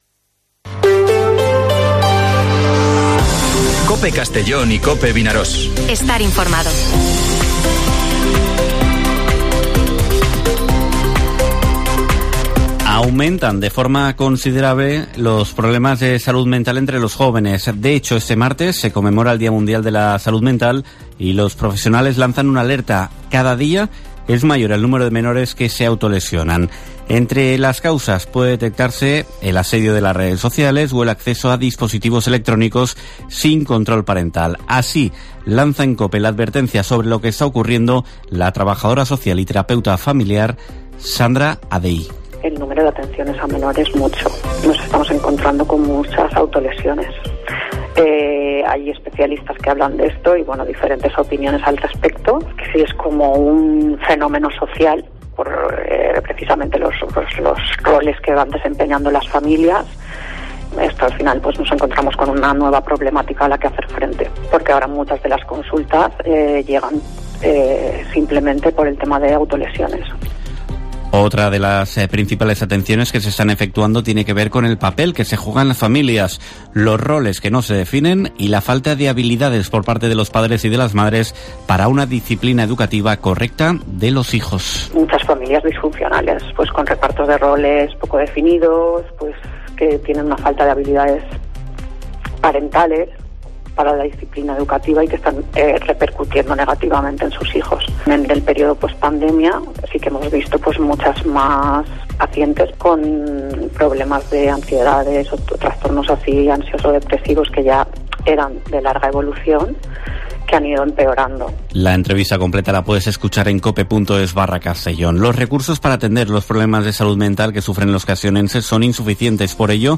Informativo Mediodía COPE en la provincia de Castellón (10/10/2023)